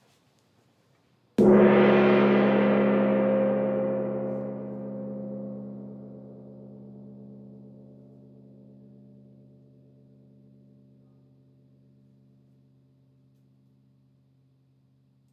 Petit-moyen-haut.wav